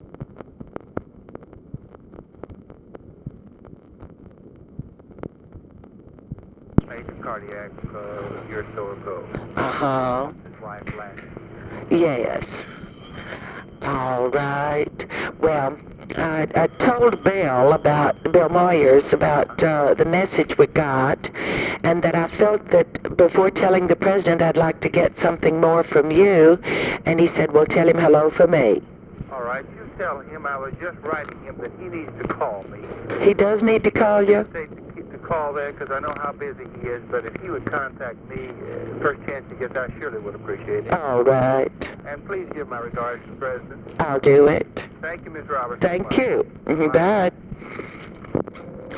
Conversation with WILLIAM CROOK
Secret White House Tapes